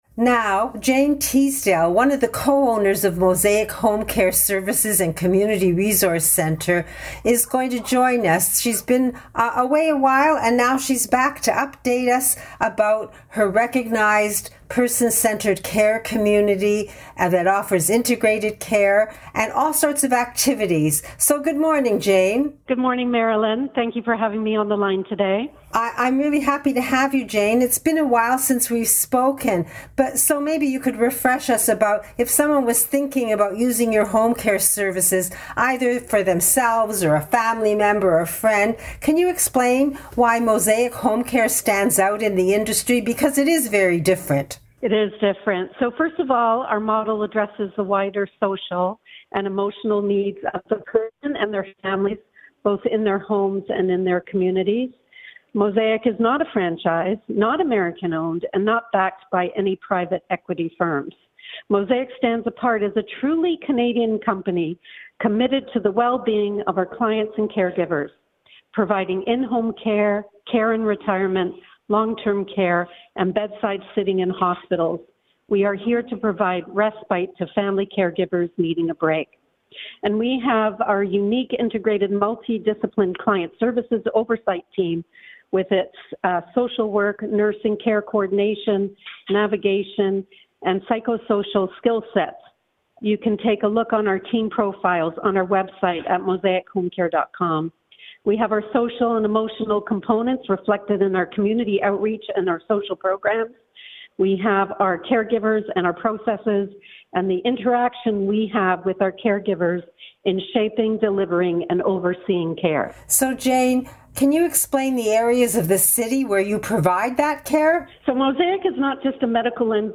Mosaic Featured on AM 740 Zoomer Radio